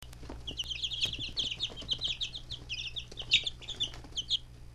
The lightest brown one has a habit of cheeping loudly and steadily when it is upset.
Click here to download a wav file of their chirping.